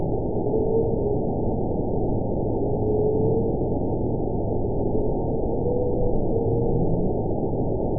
event 921185 date 05/02/24 time 05:51:59 GMT (1 year, 1 month ago) score 8.96 location TSS-AB10 detected by nrw target species NRW annotations +NRW Spectrogram: Frequency (kHz) vs. Time (s) audio not available .wav